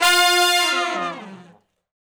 014 Long Falloff (F) unison.wav